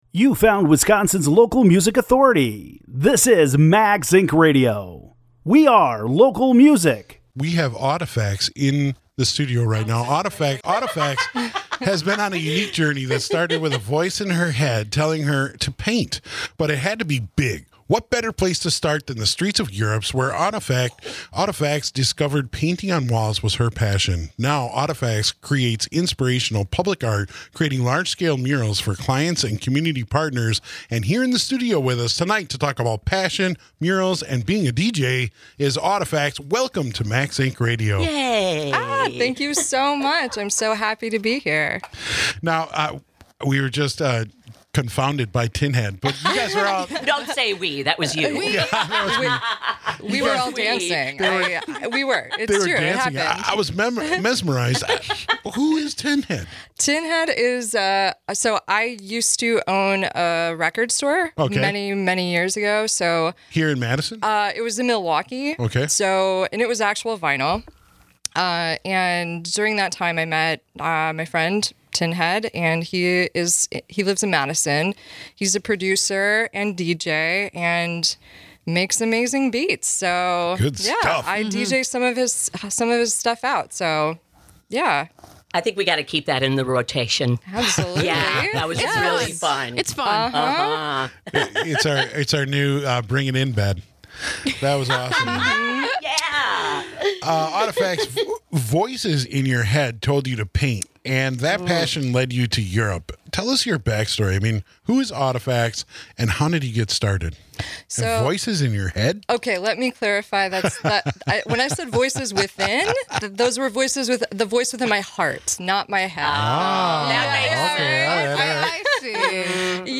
in the WMDX studio above State Street in Madison, Wisconsin.